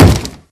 mob / zombie / wood4.ogg
wood4.ogg